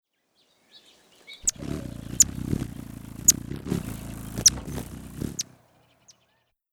Звук летающей колибри-герцога и ее писк